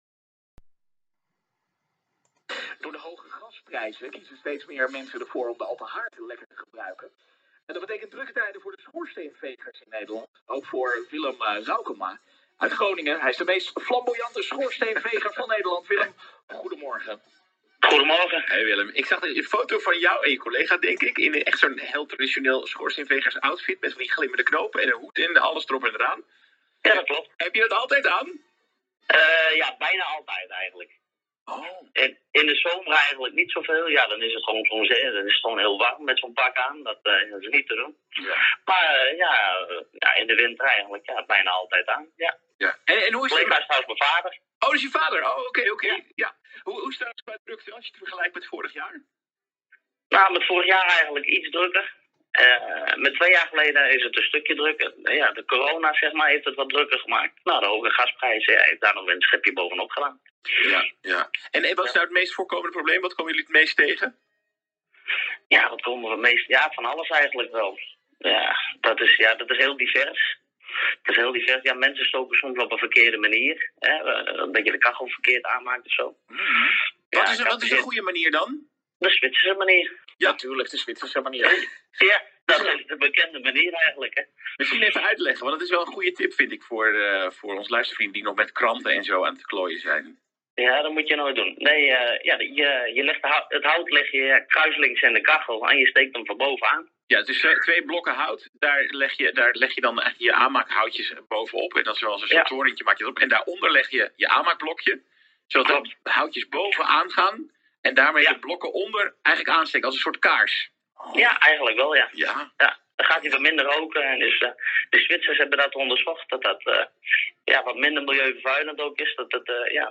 Op de landelijke radio